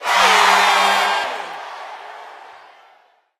brawl_score_point_01.ogg